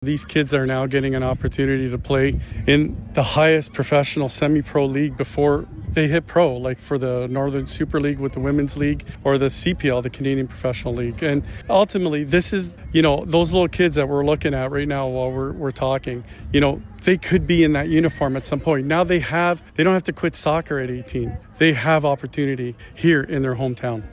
At the club’s Media Day event back on April 27th